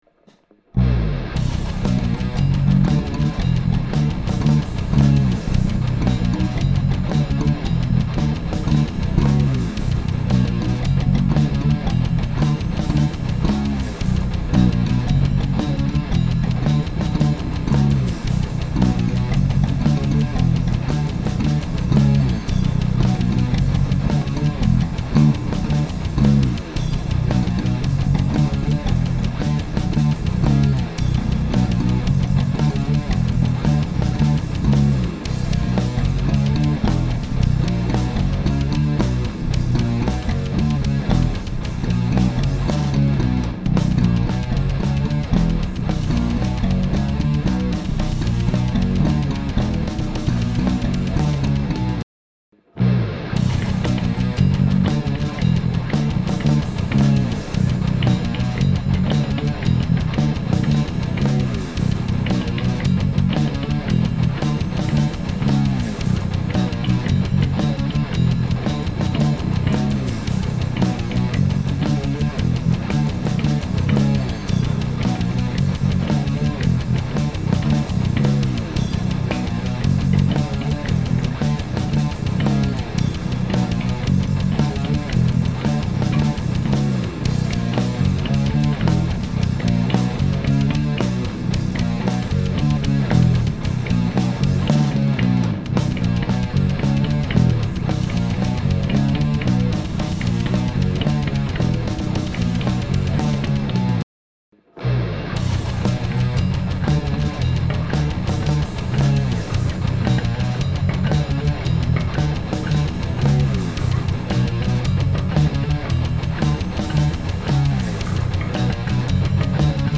Zwei kurze Parts mit je drei Bässen gespielt. Interface ist das DG Element mit der Orange 810er IR. So ist der Sound nicht komplett dröge. Ansonsten aber nichts beschönigt, einfach nur stumpf eingekloppt.